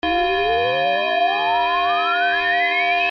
alerta
alerta.mp3